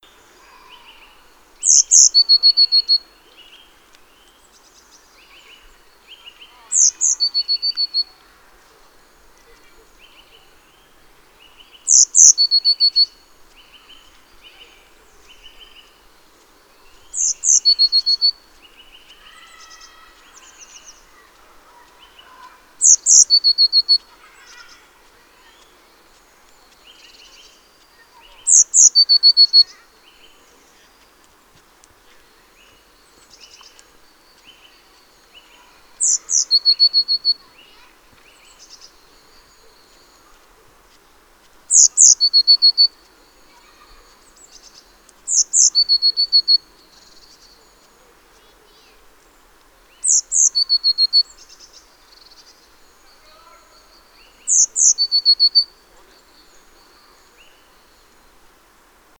Bird song
great_tit_loud-1.mp3